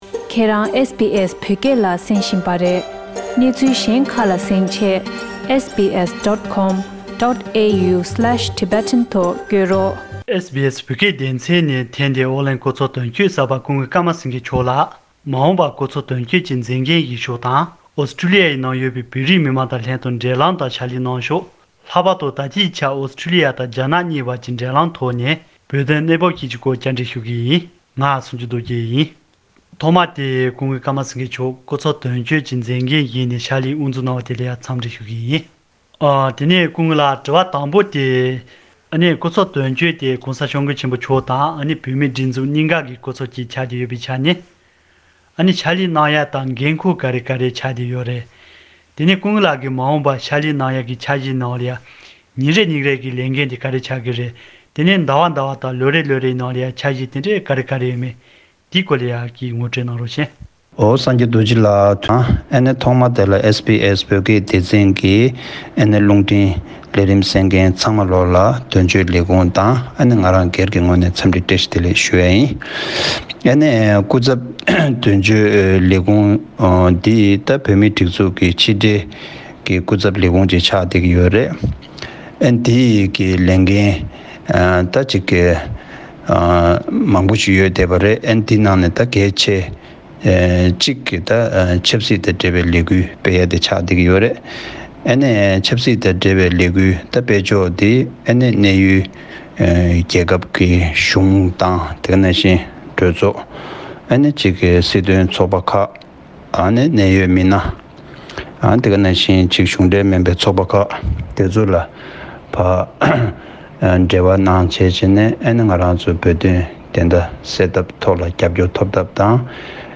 ཨོ་གླིང་སྐུ་ཚབ་དོན་གཅོད་གསར་པ་ཀརྨ་སེངྒེ་མཆོག་ལ་བཅར་འདྲི།